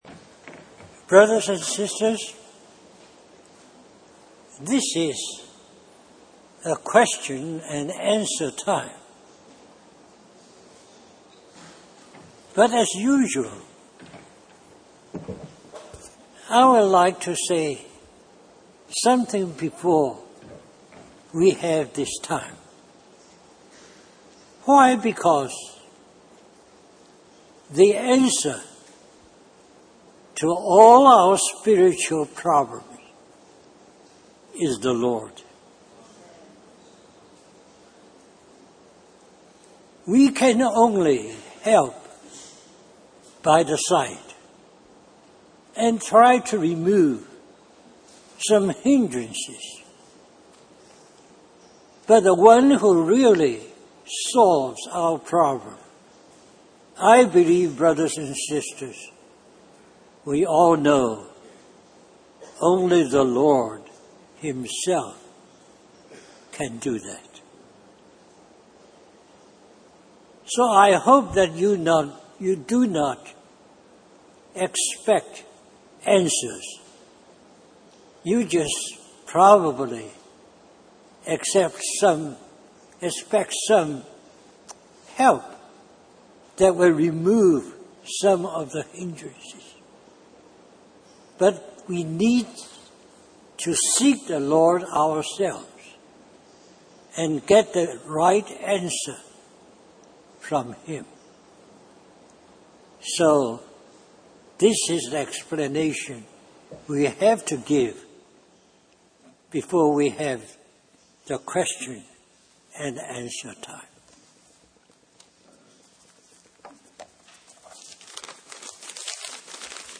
Question and Answer
Harvey Cedars Conference